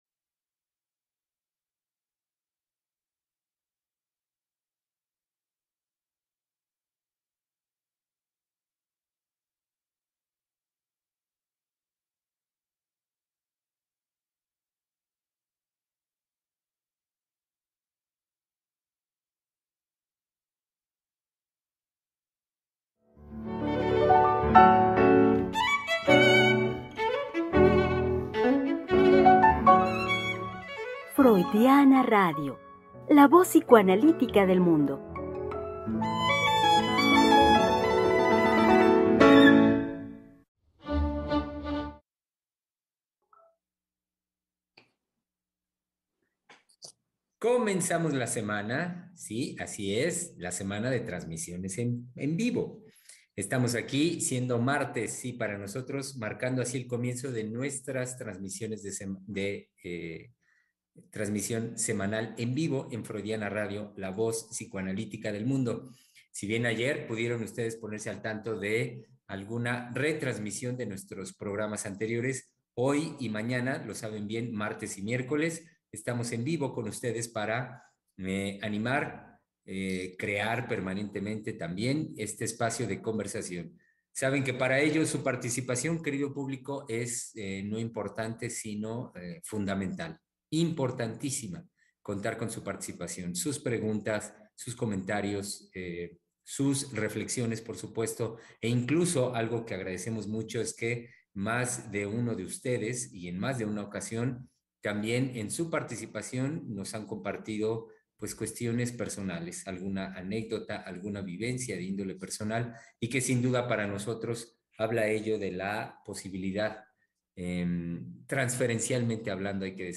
Es martes de Palabra de Hombre conversaremos con los psicoanalistas